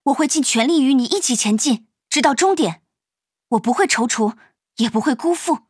【模型】GPT-SoVITS模型编号005_女_普通励志-secs